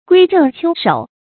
归正邱首 guī zhèng qiū shǒu
归正邱首发音
成语注音ㄍㄨㄟ ㄓㄥˋ ㄑㄧㄡ ㄕㄡˇ